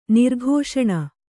♪ nirghōṣaṇa